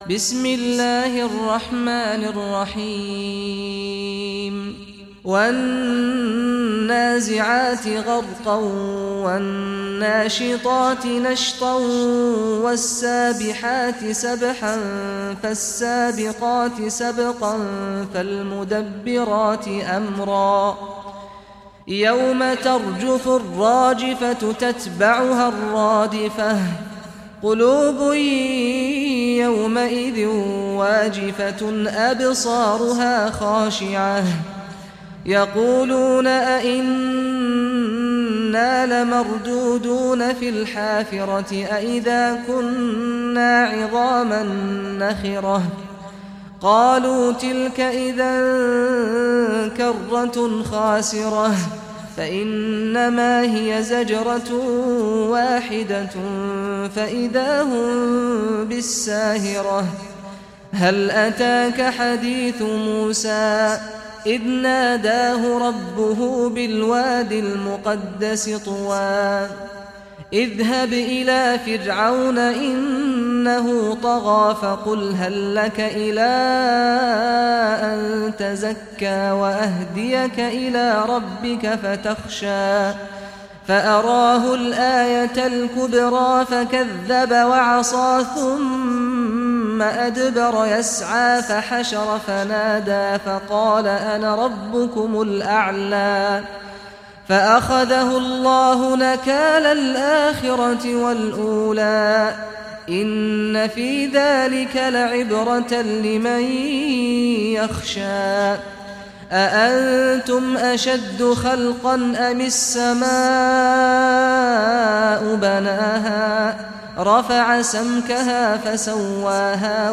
Surah An Naziat Recitation by Sheikh Saad Ghamdi
Surah An Naziat, listen or play online mp3 tilawat / recitation in Arabic in the beautiful voice of Sheikh Saad al Ghamdi.